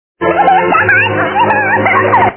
laugh.mp3